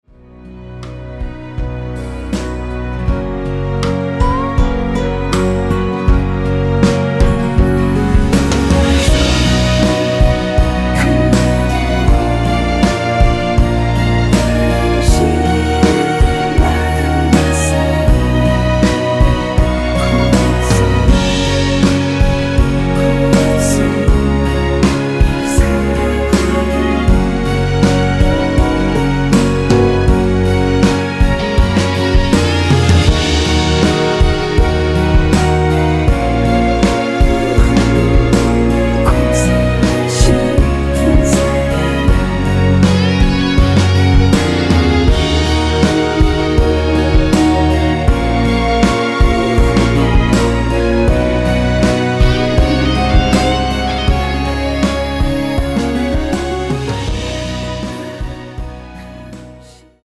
원키 멜로디와 코러스 포함된 MR입니다.(미리듣기 확인)
F#
앞부분30초, 뒷부분30초씩 편집해서 올려 드리고 있습니다.